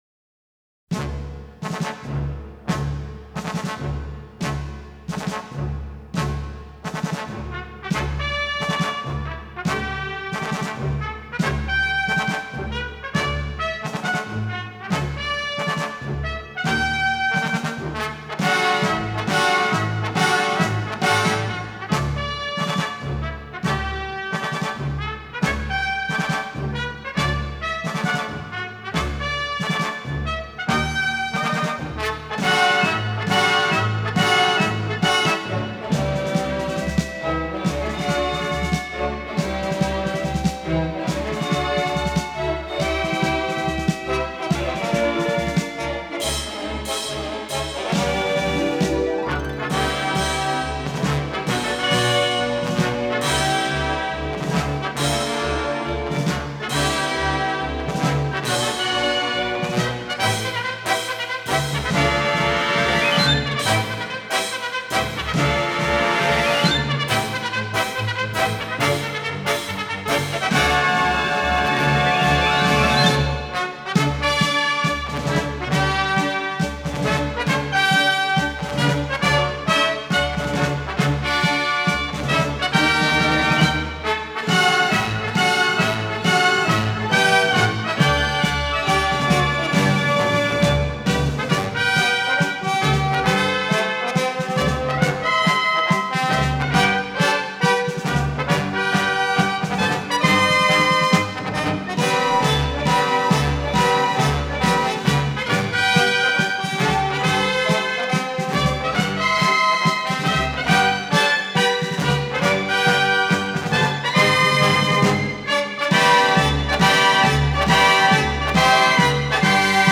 语言：纯音乐
此碟收录的进行曲现场感非同一般，声源、方位
层次感消晰，乐曲爆发力十足，使得整碟乐曲的演奏
充满着激动人心的力量，是深受古典音乐爱好者青睐